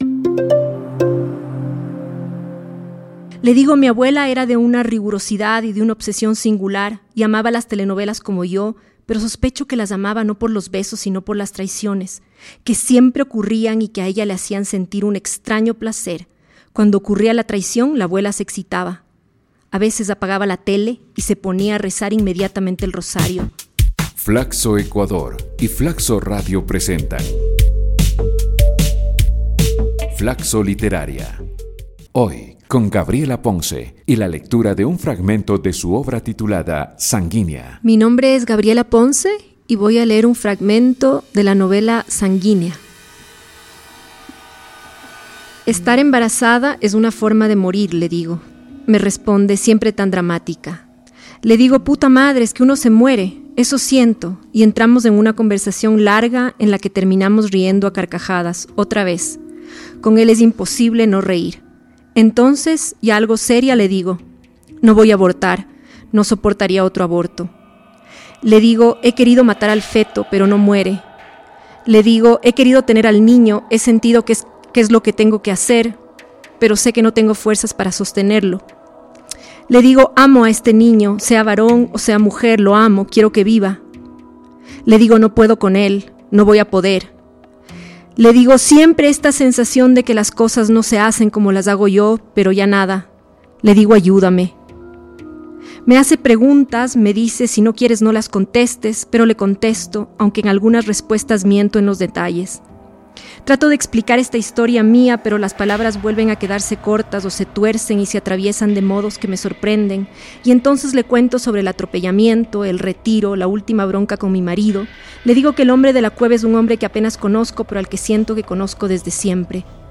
FLACSO Literaria es una iniciativa de lectura de cuentos y fragmentos de novelas que busca promover la literatura ecuatoriana, que reúne a 21 destacados escritores y escritoras quienes leerán sus obras.